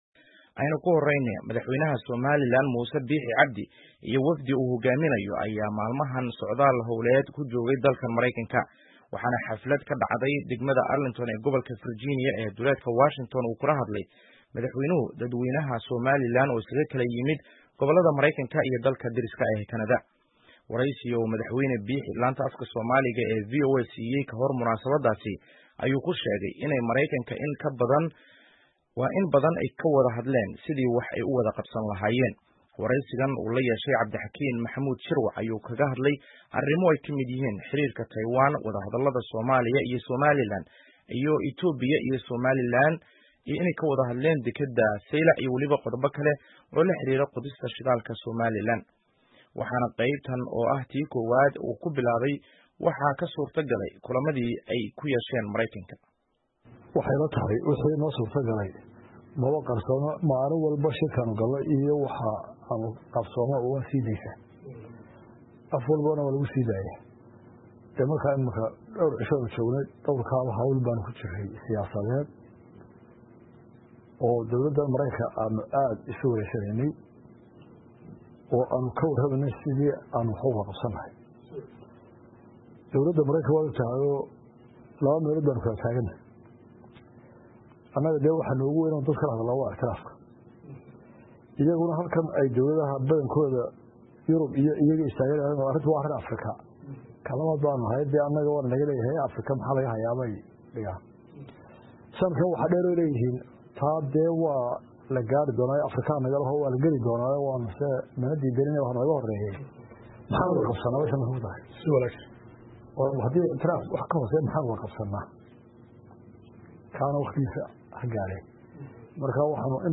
Muuse Biixi oo shaaciyey in Mareykanku usoo dabcay Somaliland (Wareysi cusub)